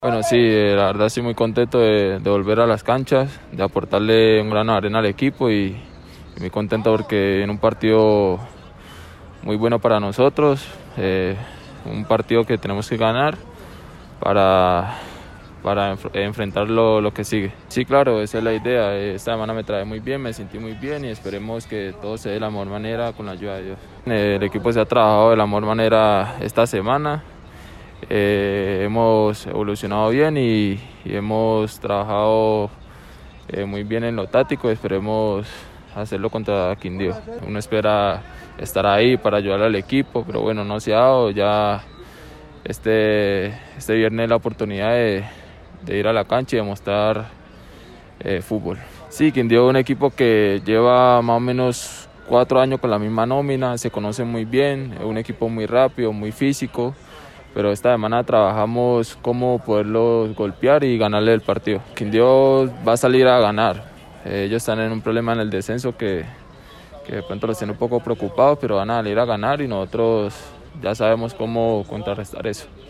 Zona Mixta: